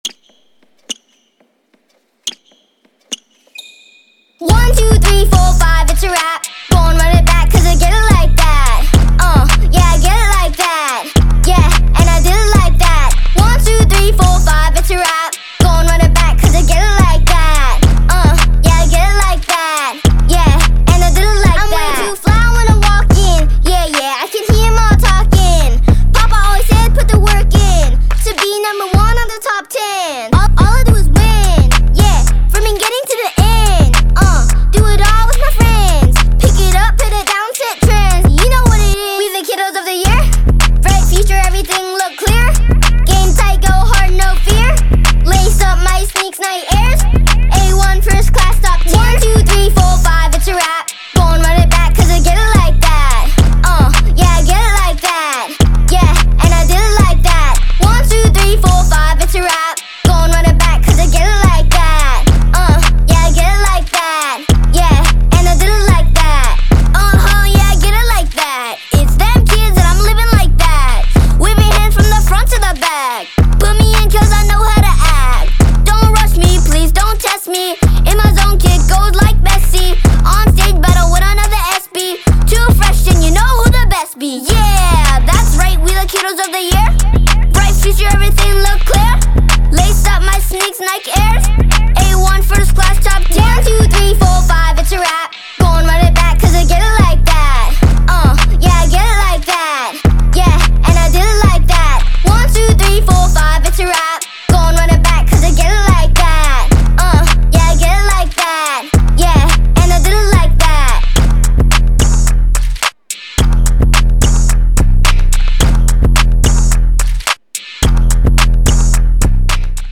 Трек размещён в разделе Зарубежная музыка / Поп.